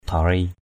/d̪ʱɔ-ri:/ (d.) bông tai. earrings.